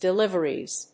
発音記号
• / dɪˈlɪvɝiz(米国英語)
• / dɪˈlɪvɜ:i:z(英国英語)